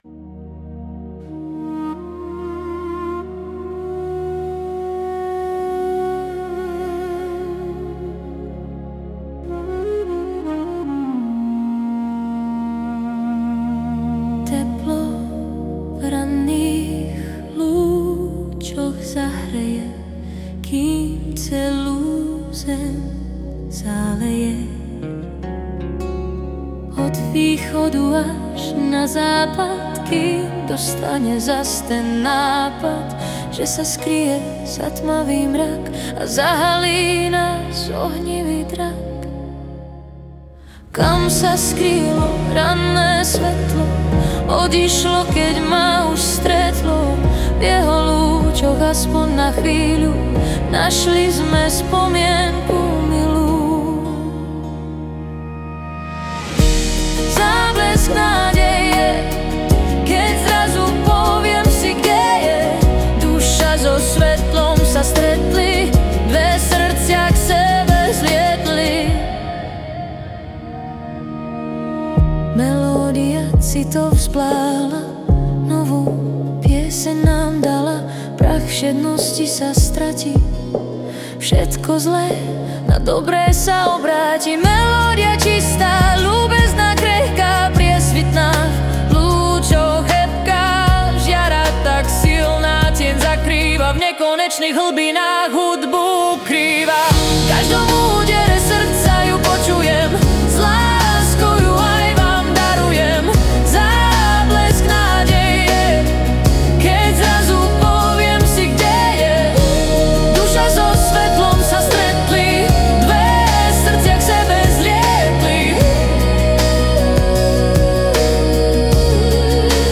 Hudba a spev AI